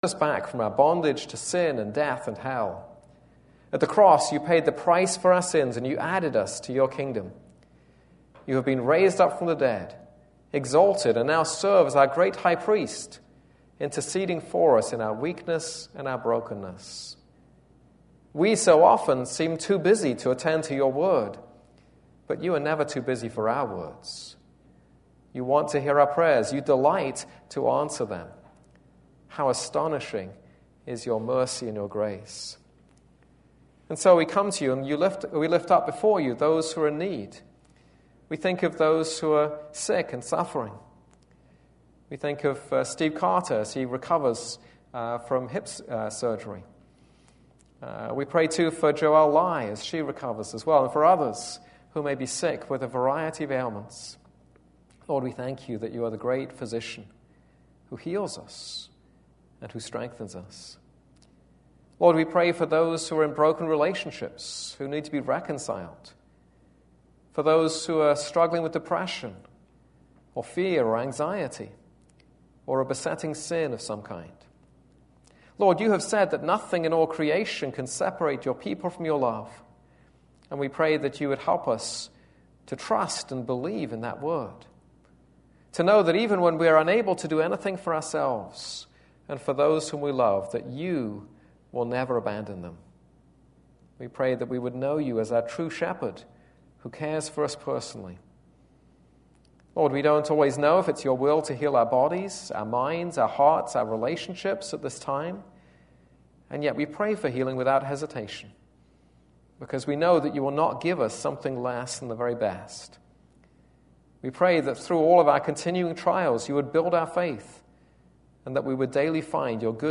This is a sermon on Song of Songs 3:6-4:7.